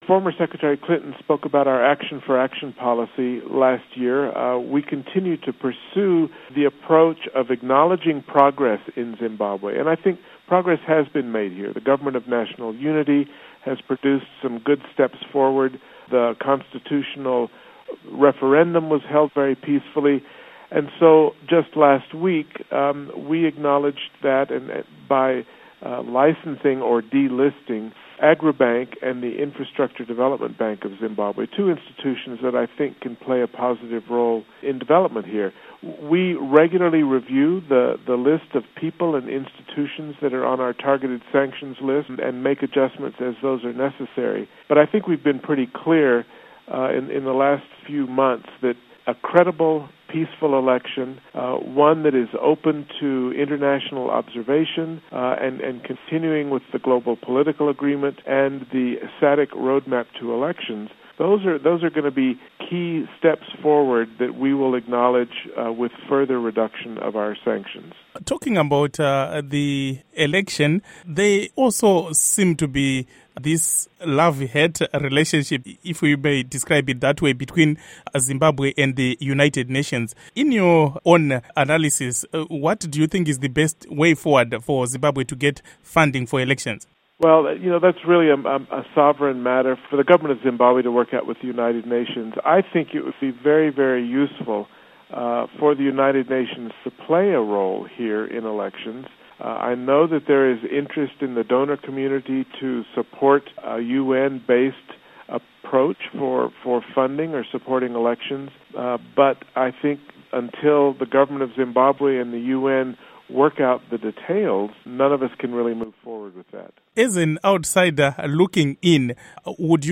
Interview With Bruce Wharton